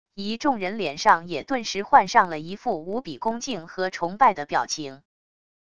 一众人脸上也顿时换上了一副无比恭敬和崇拜的表情wav音频生成系统WAV Audio Player